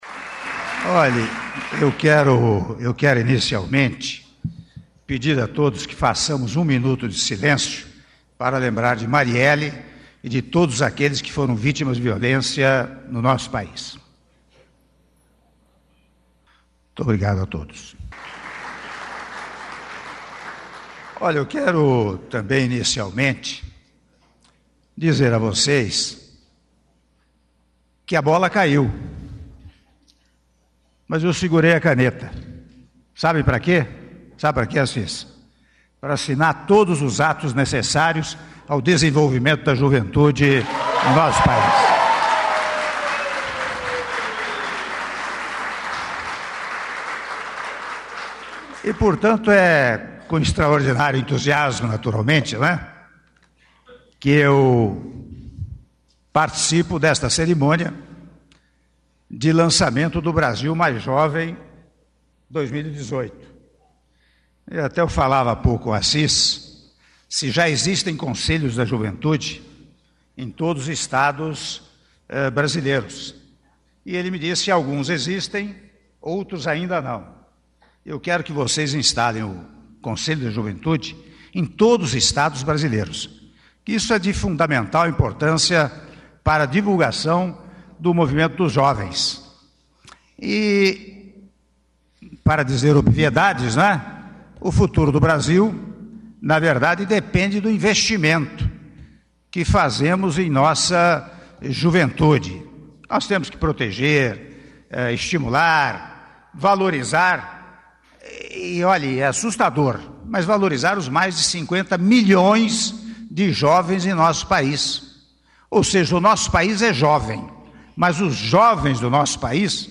Áudio do discurso do Presidente da República, Michel Temer, durante Cerimônia de Lançamento do Brasil Mais Jovem 2018 - (08min14s) - Brasília/DF